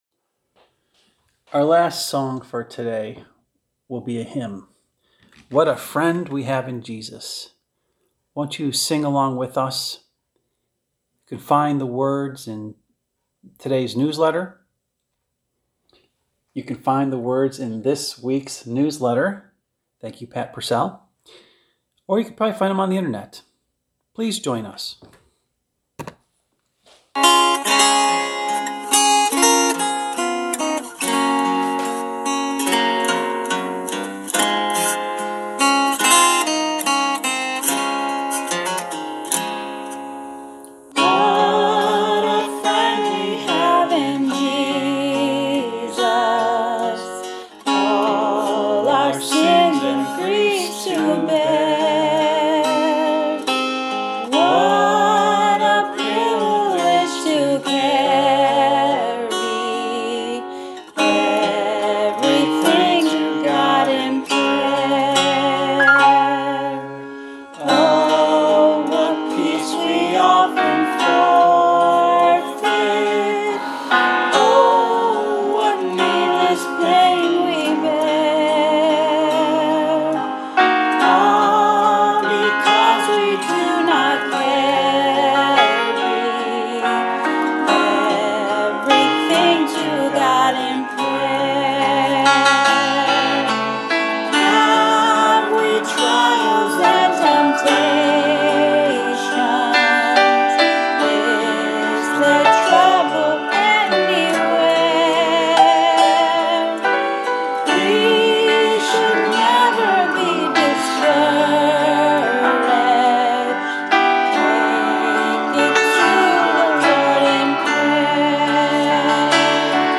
Special Music